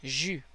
Ääntäminen
Canada (Montréal) - jus
IPA: /ʒy/